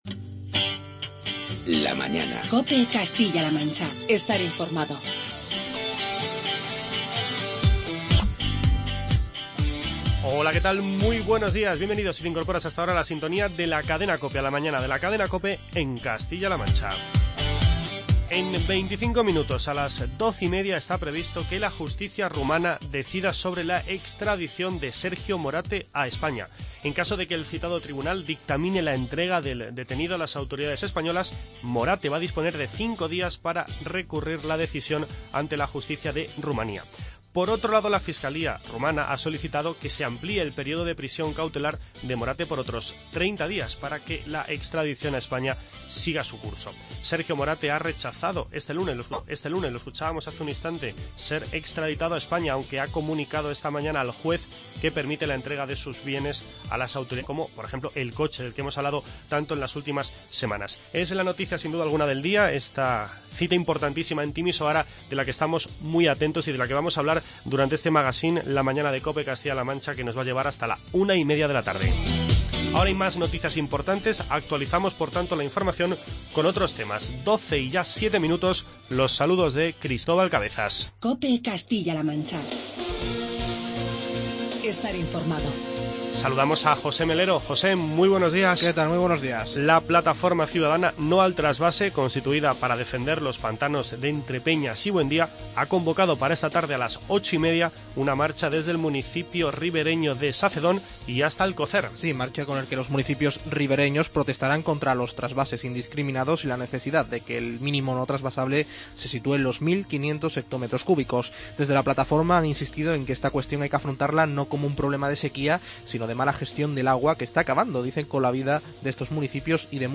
Charlamos con el alcalde de Sonseca, Juan Carlos Palencia.